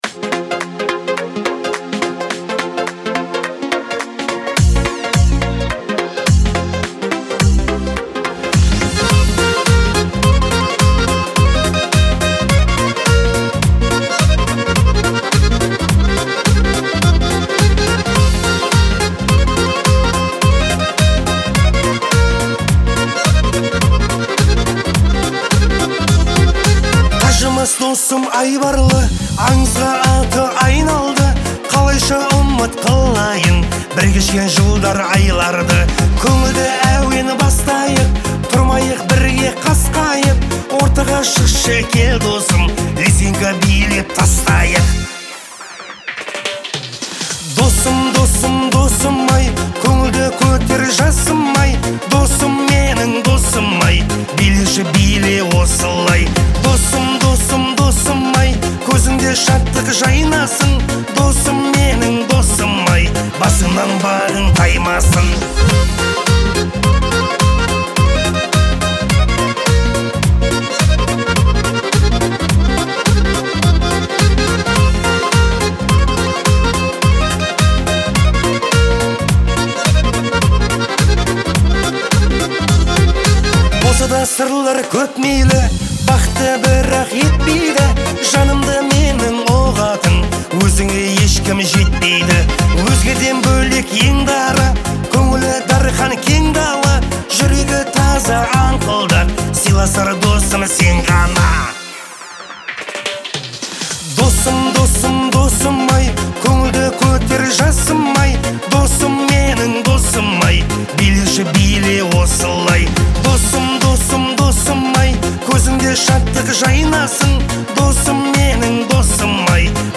сочетая традиционные мелодии с современным звучанием